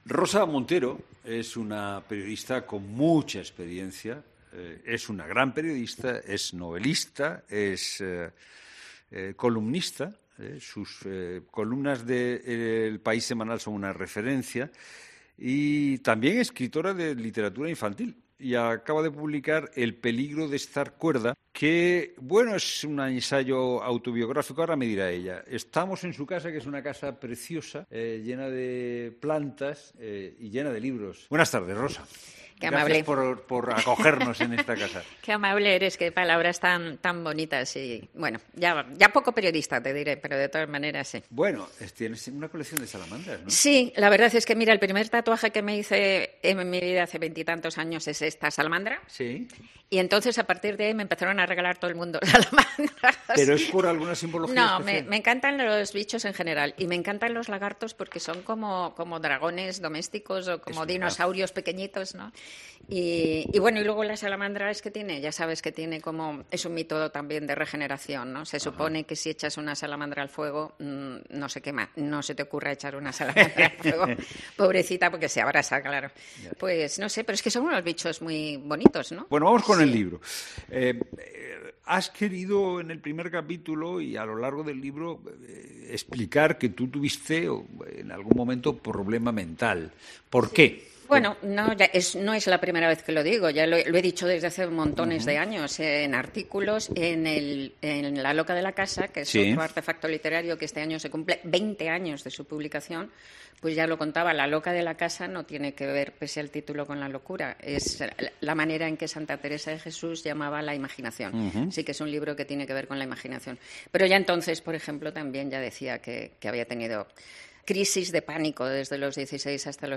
En La Tarde de COPE hablamos con ella.